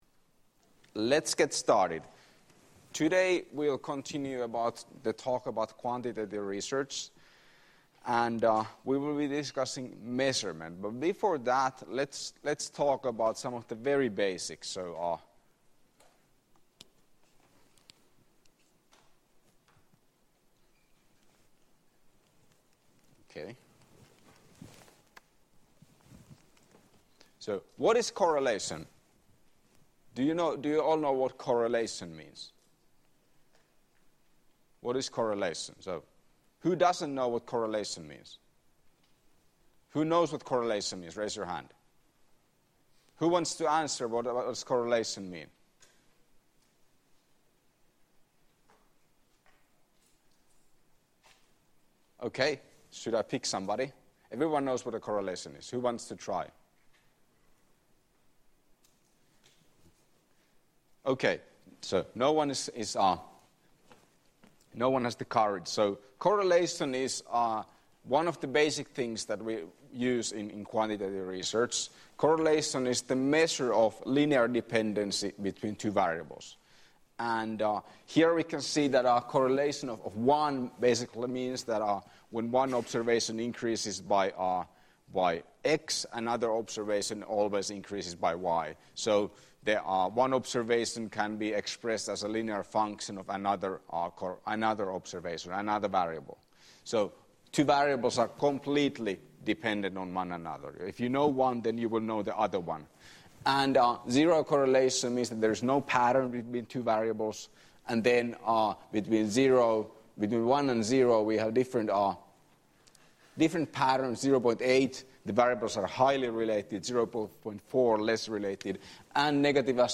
Lecture 12.9.2016 — Moniviestin